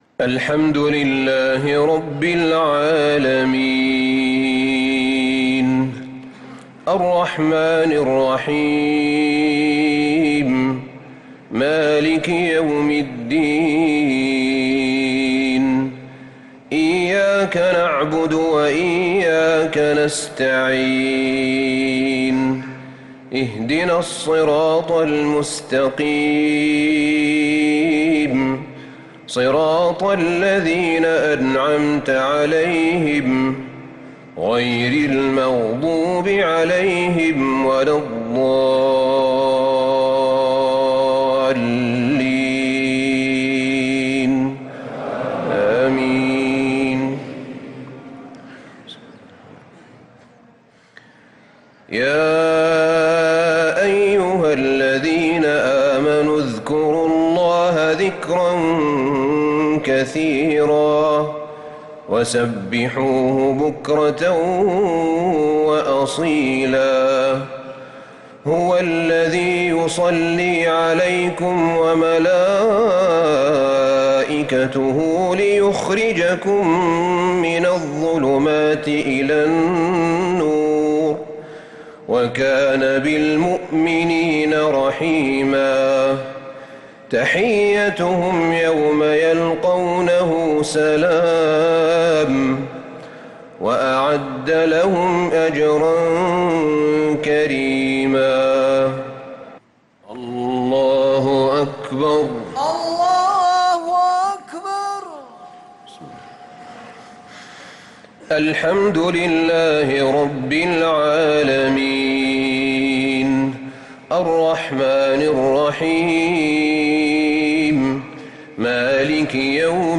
صلاة العشاء للقارئ أحمد بن طالب حميد 28 رمضان 1445 هـ
تِلَاوَات الْحَرَمَيْن .